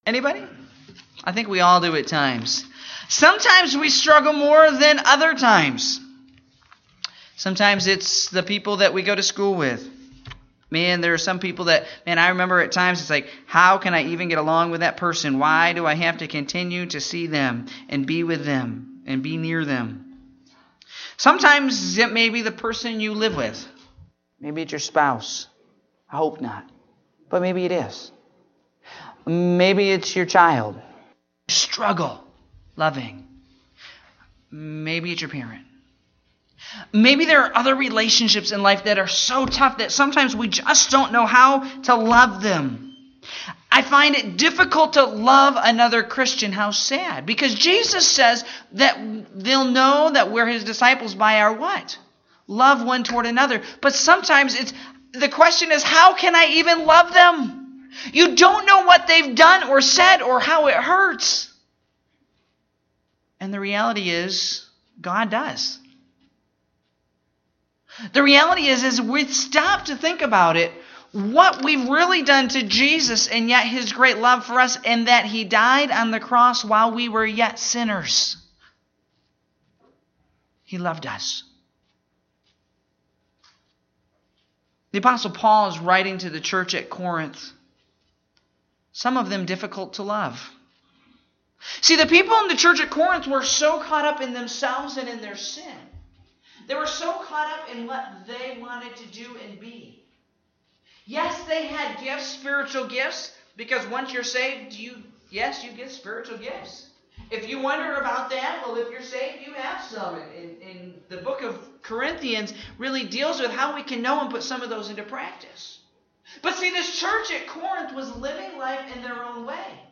Morning Service (11/12/2017)